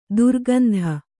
♪ durgandha